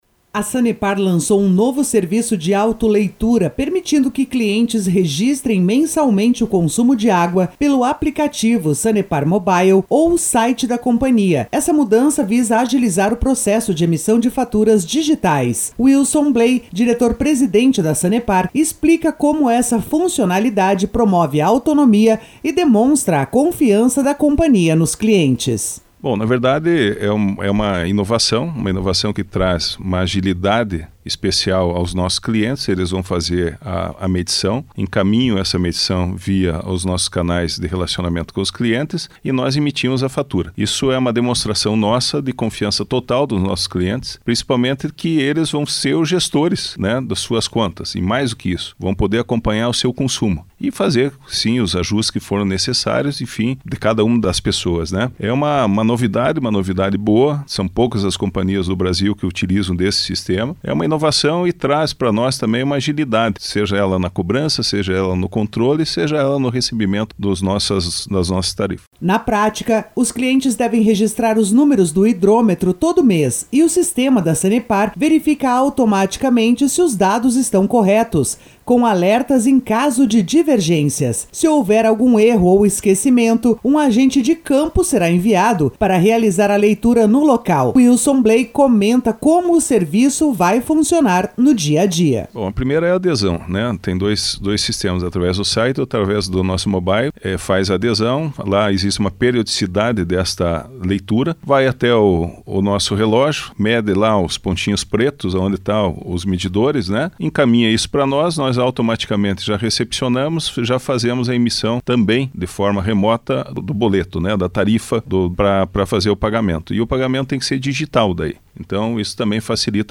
MATERIA-AUTOLEITURA-SANEPAR.mp3